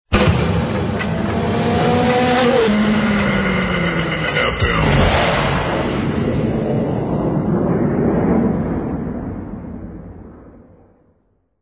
All tracks encoded in mp3 audio lo-fi quality.